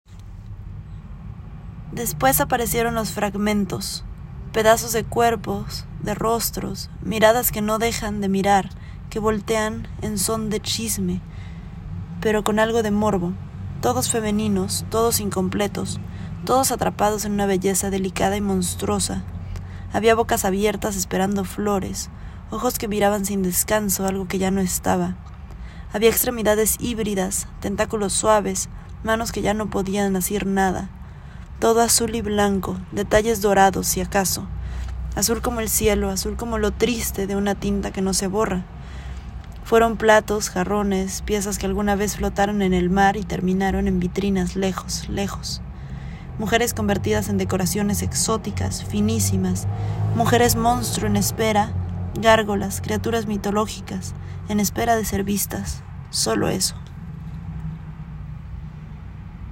Cada fragmento fue escrito como un flujo de conciencia, grabado en audio con mi voz y acompañado por imágenes específicas, evocadas directamente por lo que se cuenta o añadidas por asociación libre.